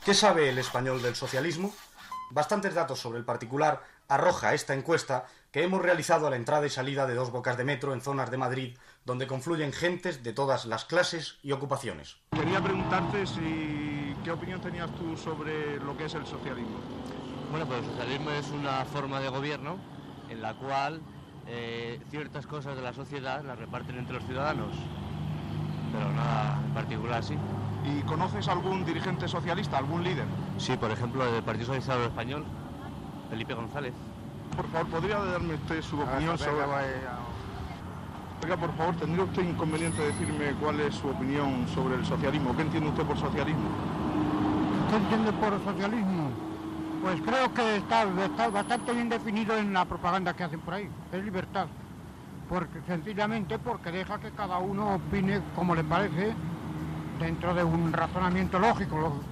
Enquesta sobre "què sap vostè del socialisme?"
Informatiu